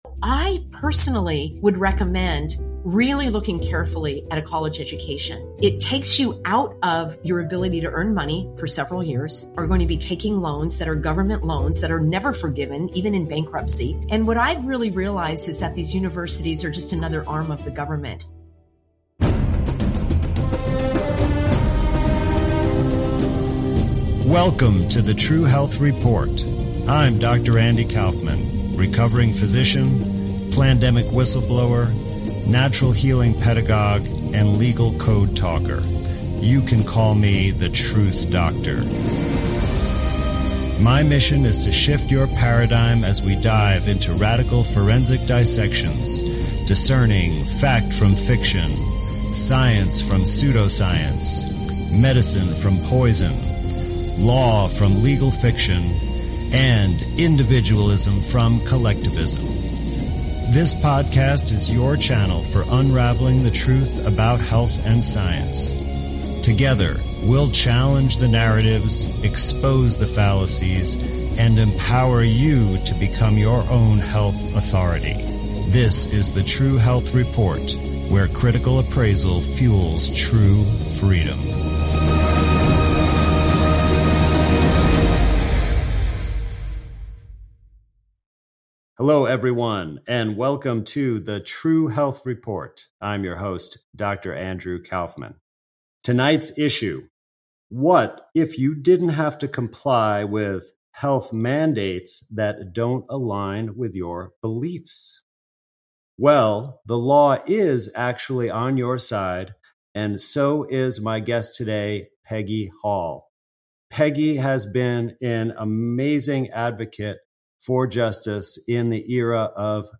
She expresses her concerns about the current education system, particularly the pressure on teachers and the focus on standardized tests. ➡ The speaker, a former educator, questions the value of traditional university education, citing its high cost, excessive management, and potential for waste. She suggests that practical experience, like apprenticeships, can often provide more valuable learning and financial benefits.